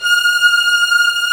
Index of /90_sSampleCDs/Roland LCDP13 String Sections/STR_Combos 2/CMB_Hi Strings 1
STR VIOLIN0B.wav